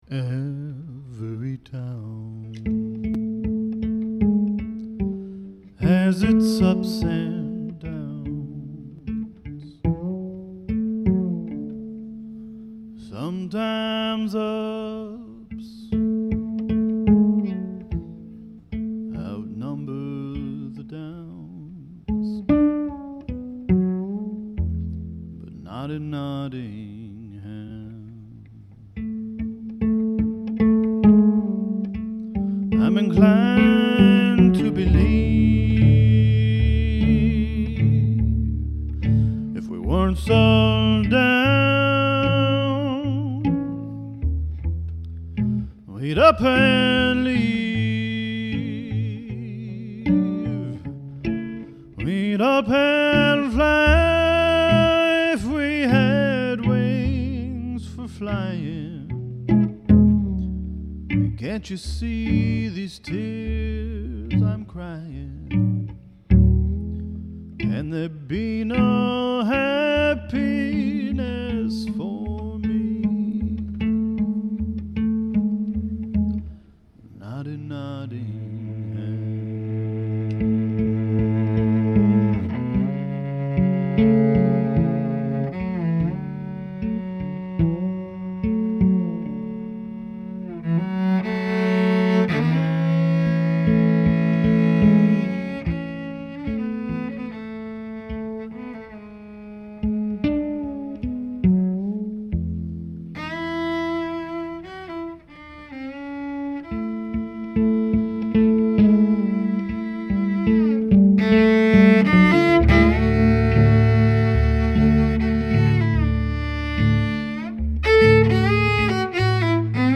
live with loop